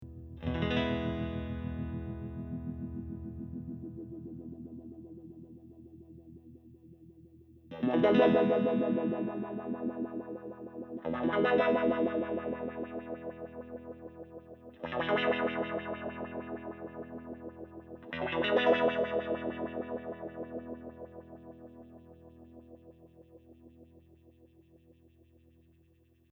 The sweep modes are ÒchoppyÓ and Òsmooth,Ó which refer to how quickly the sweep travels along the frequency range.
Sweeping the HF Setpoint control:  Clock Trigger, Choppy Mode